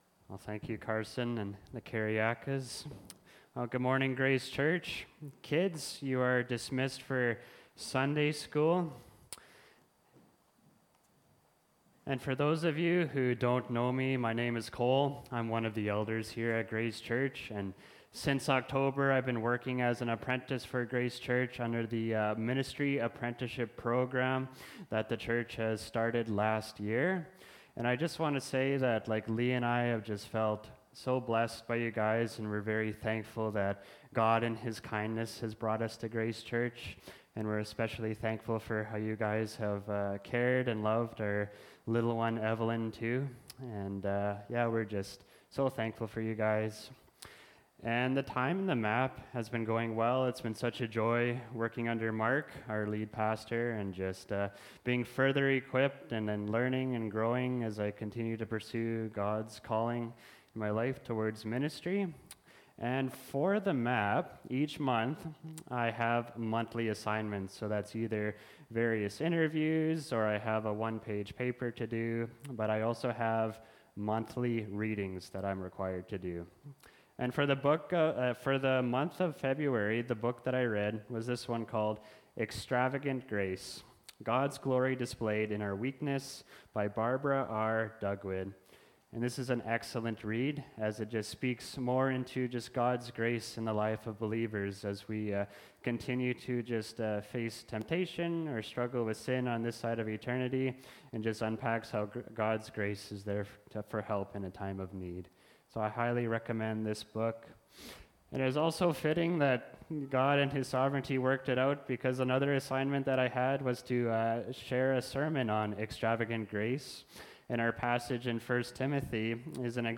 Mar 02, 2025 Extravagant Grace (1 Timothy 1:12–16) MP3 SUBSCRIBE on iTunes(Podcast) Notes Discussion Sermons in this Series This sermon was recorded in Salmon Arm and preached in both campuses.